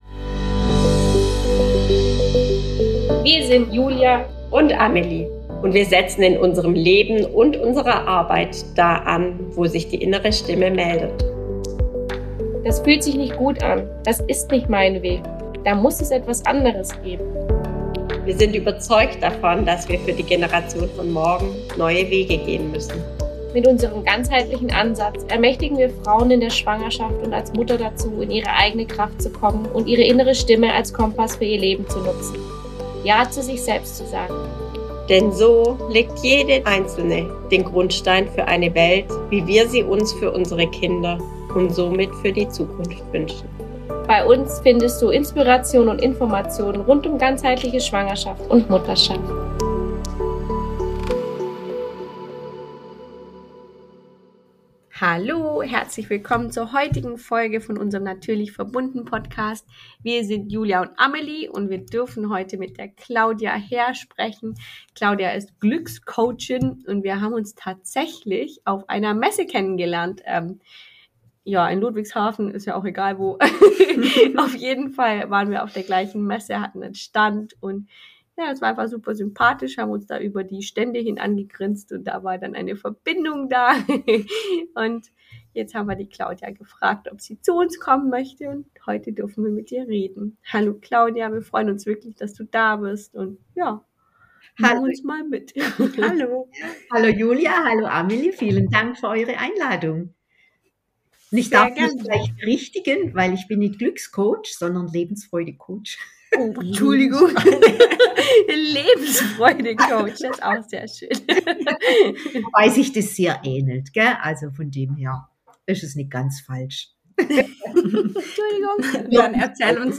Ein herzliches Gespräch über Mut, Angst, Scham, Freude, Glück und den Reichtum des Lebens.